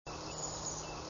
White-throated Sparrow, Amboy Avenue, Perth Amboy, New Jersey, high "tips", 4/13/03 (5kb)
sparrow_WT_high_tips_717.wav